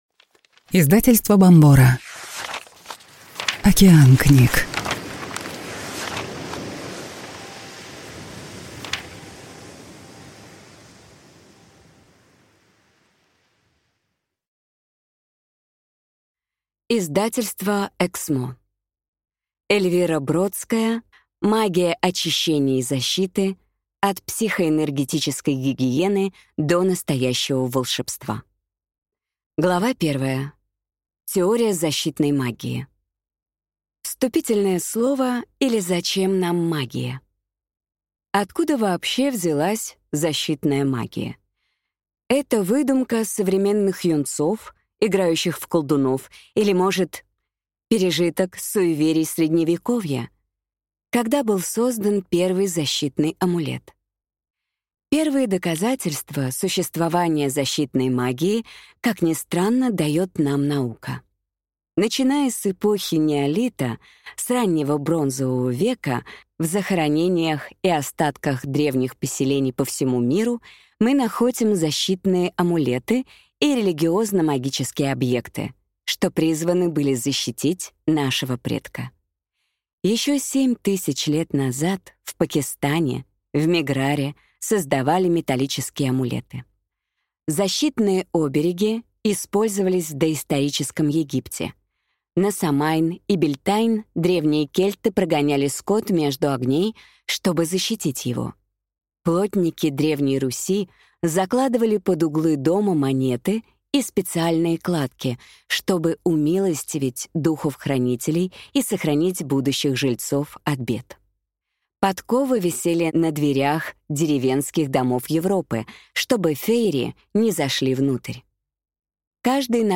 Аудиокнига Магия очищения и защиты. От психоэнергетической гигиены до настоящего волшебства | Библиотека аудиокниг